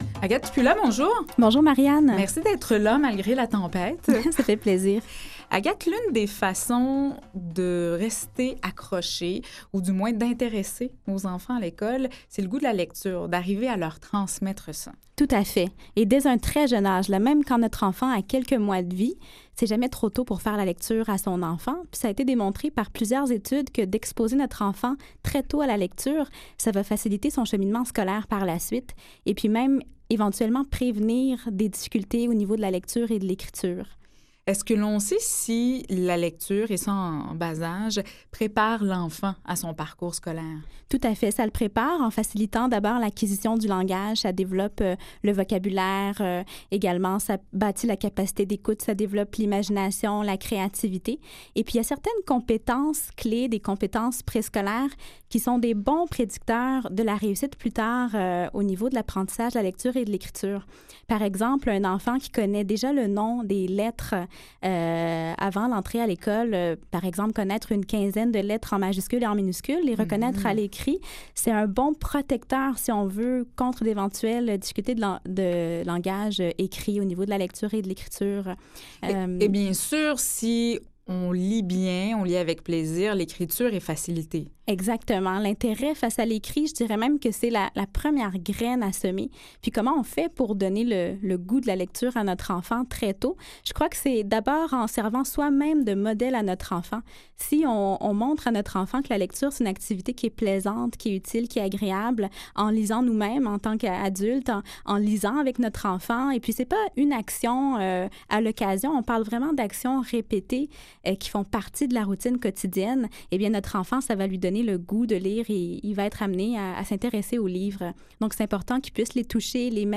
Mardi 16 février 2016 – Un magazine de services axé sur la promotion de la santé et de saines habitudes de vie. Au menu : conseils, stratégies de prévention, outils et ressources pour conserver ou retrouver sa forme physique et mentale.